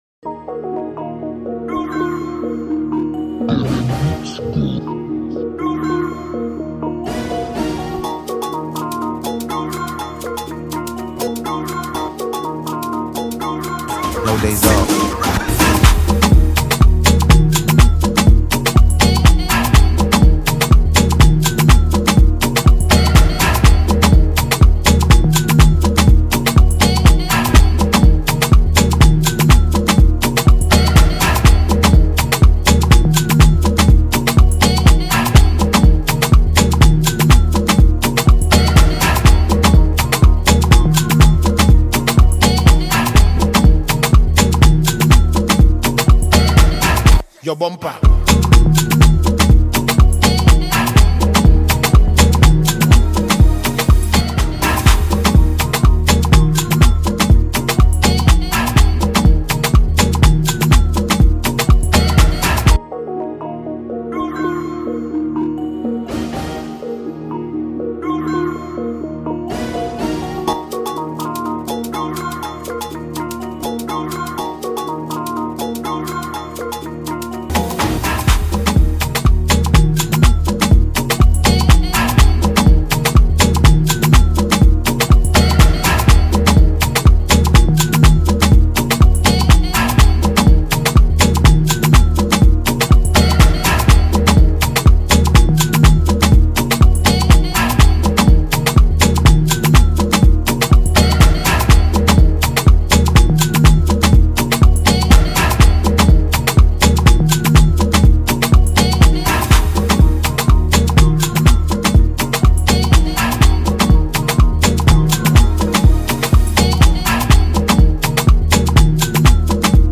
free remake beat Instrumental